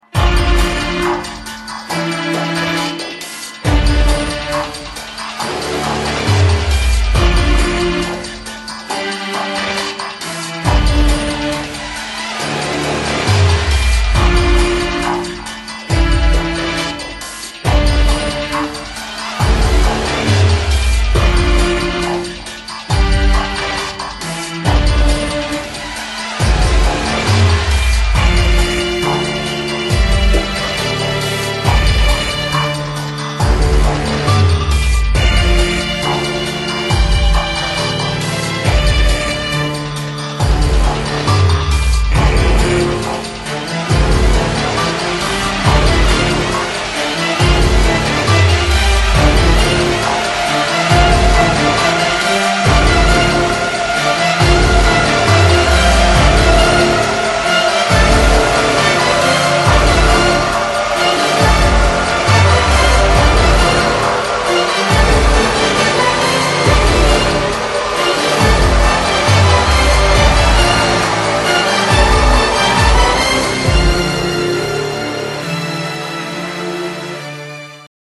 Категория: Музыка из фильмов ужасов